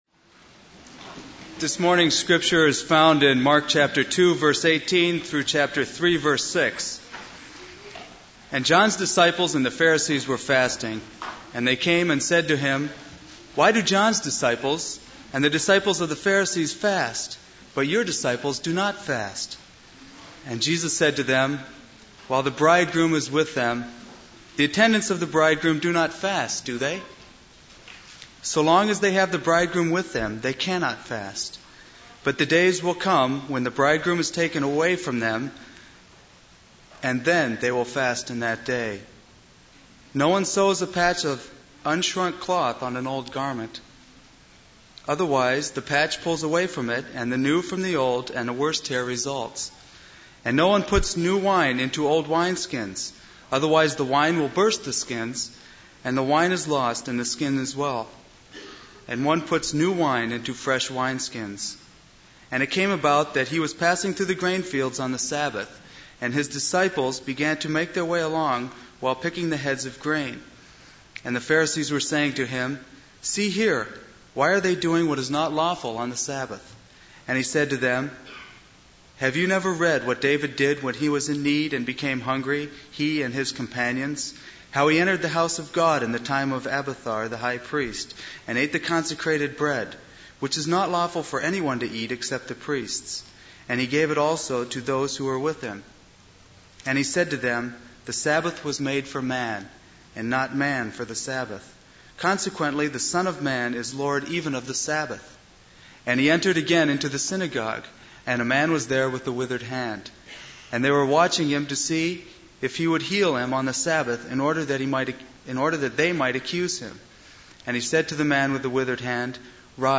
This is a sermon on Mark 2:18-3:6.